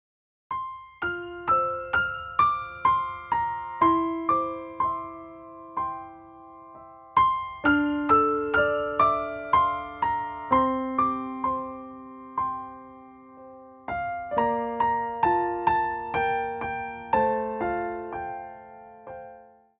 Wersja demonstracyjna:
63 BPM
c-moll